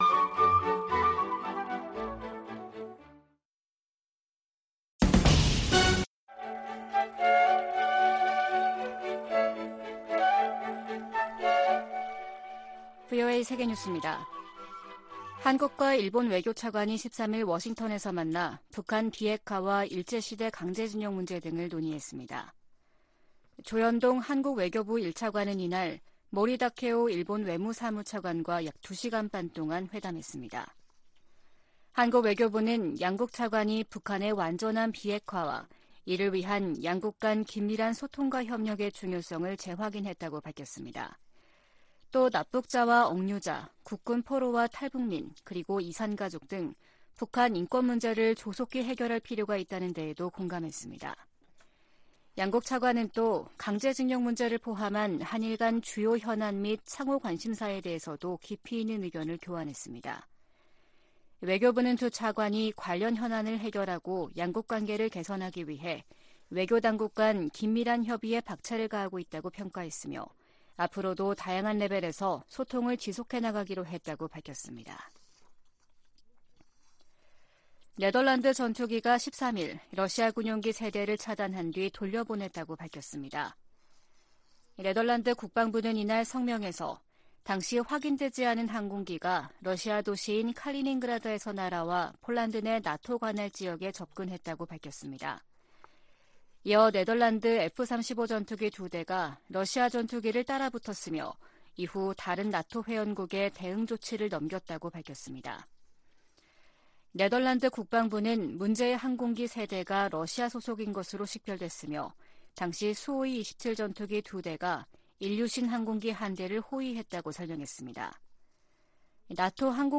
VOA 한국어 아침 뉴스 프로그램 '워싱턴 뉴스 광장' 2023년 2월 15일 방송입니다. 미국과 한국, 일본의 외교 차관들이 워싱턴에서 회의를 열고 북한의 핵과 미사일 위협에 대응해 삼각 공조를 강화하기로 했습니다. 미국 정부는 중국 등에 유엔 안보리 대북 결의의 문구와 정신을 따라야 한다고 촉구했습니다. 백악관은 중국의 정찰풍선이 전 세계 수십 개 국가를 통과했다는 사실을 거듭 확인했습니다.